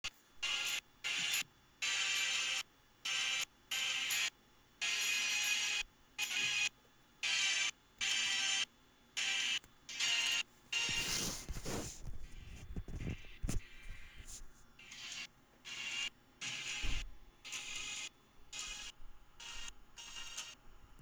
Bluetooth audio playback crackling.
Anyone experiencing audio playback crackling issues? 5 different headset does the same on my first fenix 7 pro and on my latest fenix 7 pro.